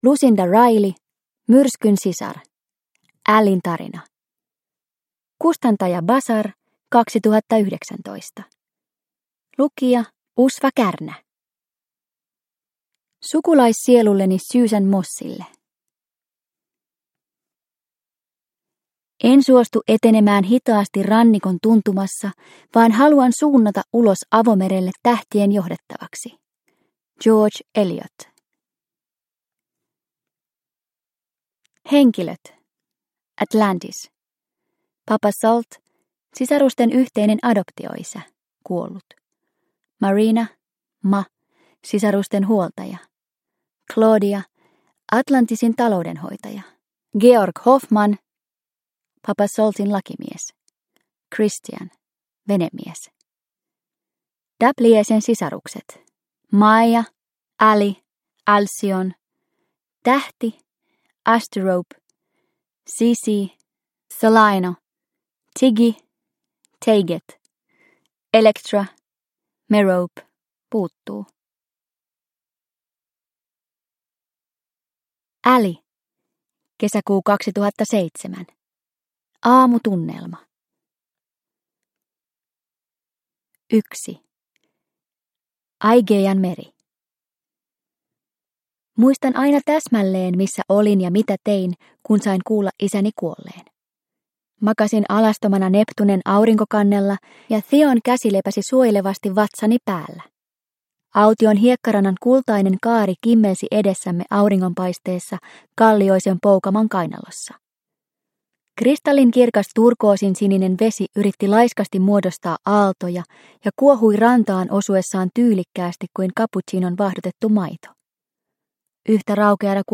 Myrskyn sisar – Ljudbok – Laddas ner